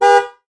honk5.ogg